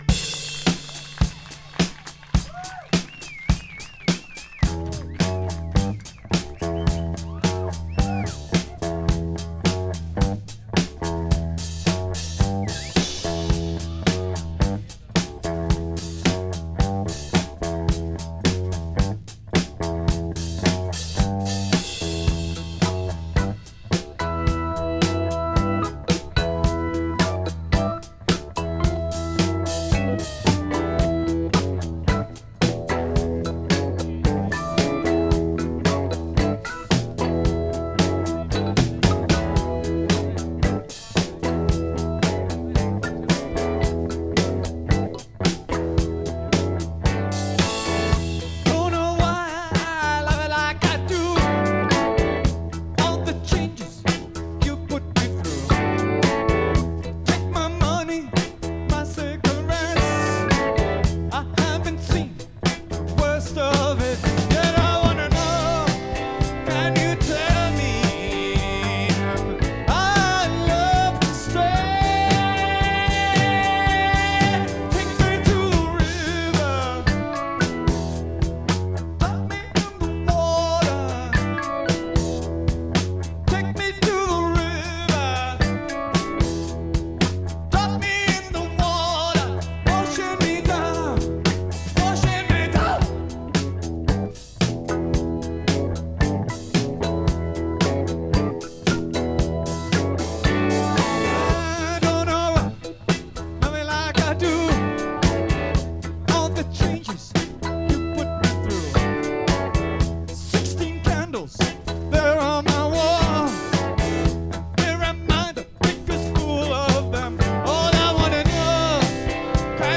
Recorded November 17th, 1977 in Massachusetts
Source: vinyl rip